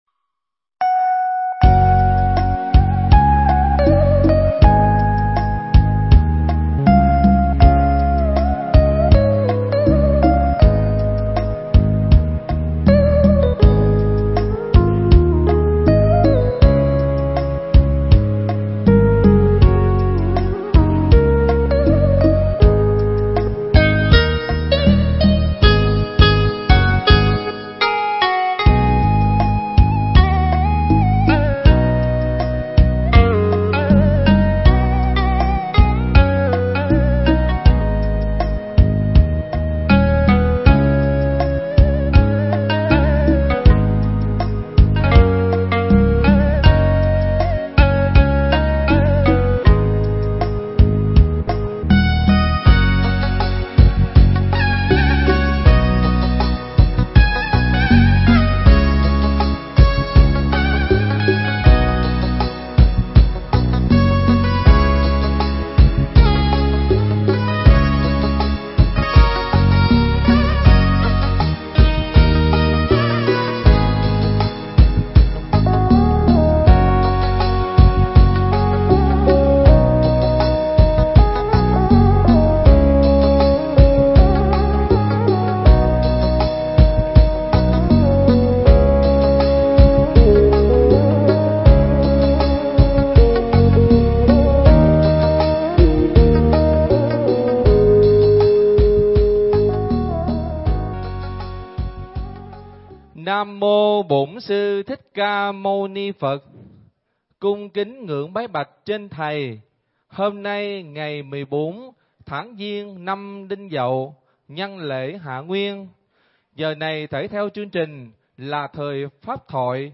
Mp3 Pháp Thoại Ý Nghĩa Việc Xin Lộc Đầu Năm
giảng nhân lễ Thượng Nguyên tại Tu Viện Tường Vân ngày 10-11 tháng 2 năm 2017